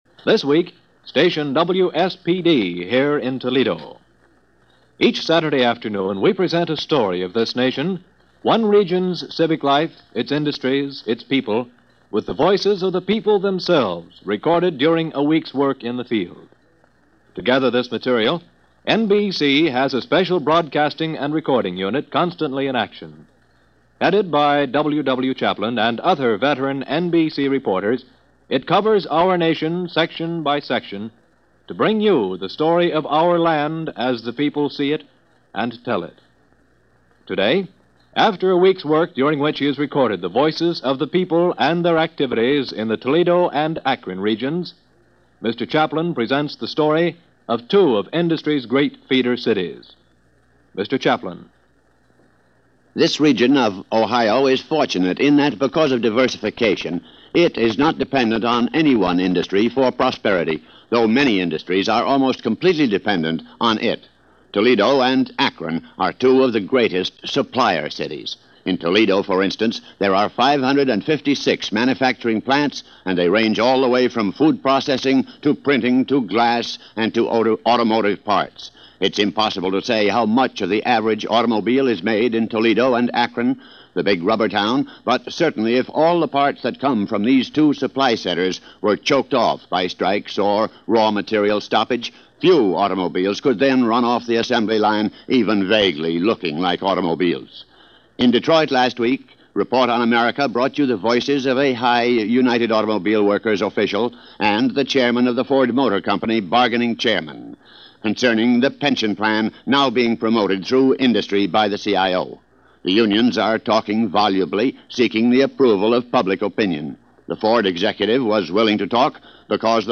– NBC Radio – Report On America: Toledo – October 15, 1949 – Gordon Skene Sound Collection –